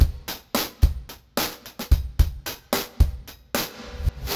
RemixedDrums_110BPM_47.wav